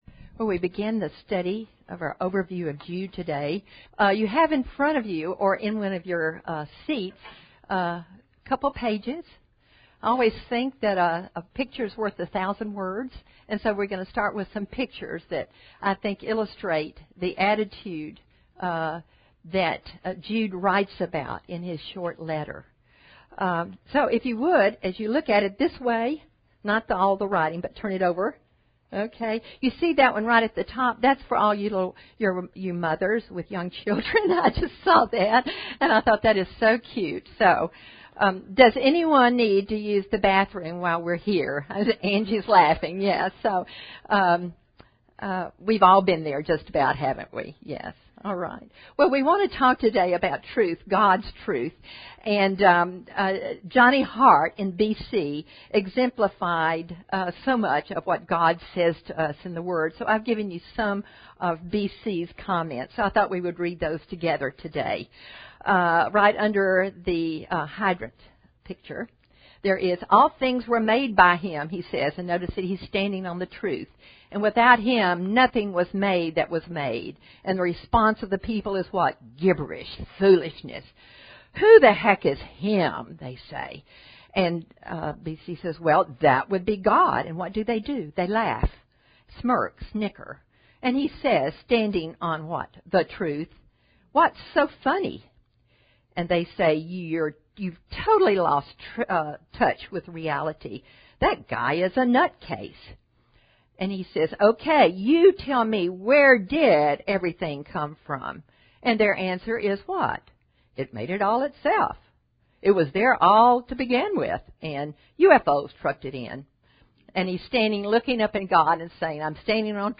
Women Women - Bible Study - Jude Audio Series List Next ▶ Current 1.